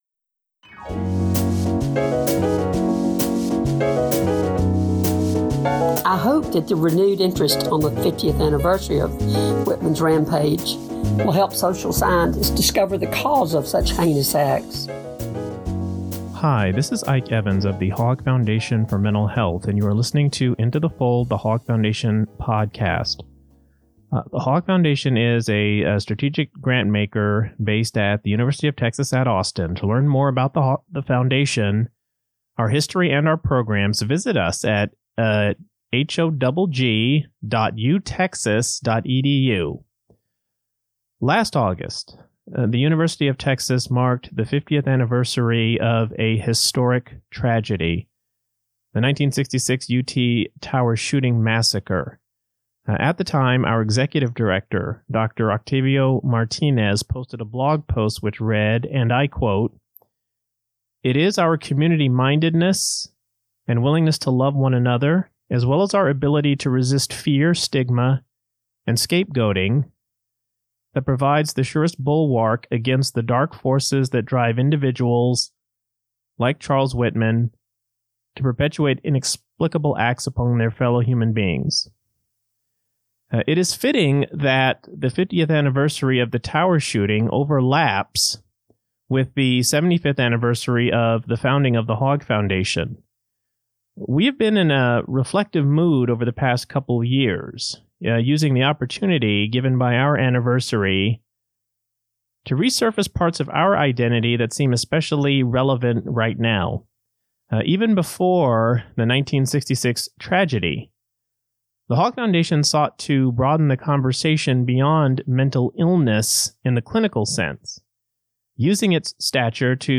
The 1966 shooting rampage by Charles Whitman at The University of Texas at Austin was not only a historic tragedy, but it was a watershed event in the history of the Hogg Foundation. In this episode we hear from two chroniclers of the shooting and its aftermath